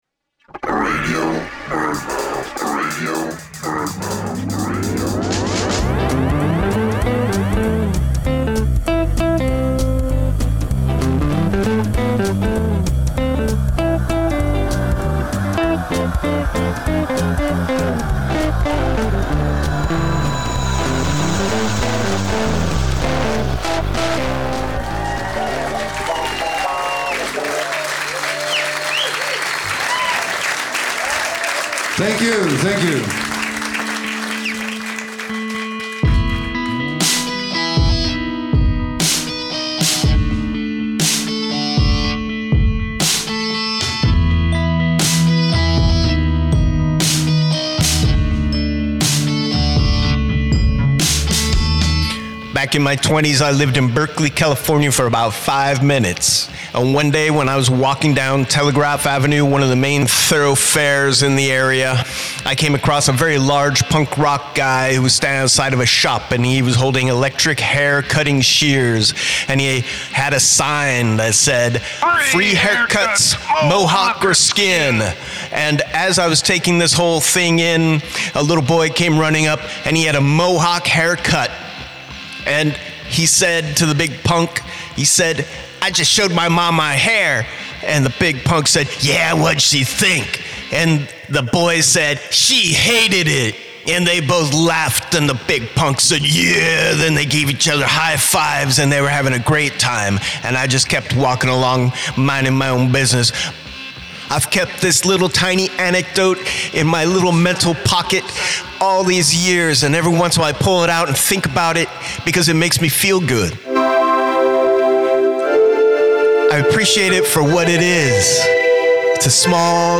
"Radio BirdMouth" is a loose-form audio road trip woven together from fragmentary spoken word narratives and sound manipulations.